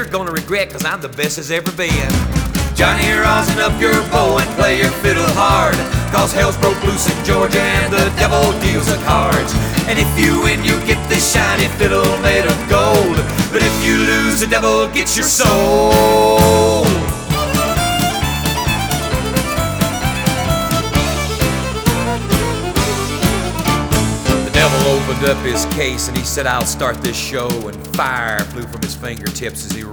• Country
The song is written in the key of D minor.